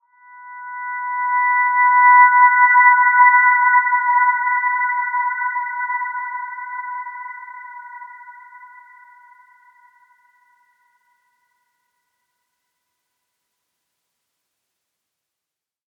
Dreamy-Fifths-B5-p.wav